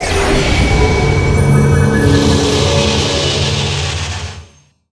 Magic_SpellResurrect03.wav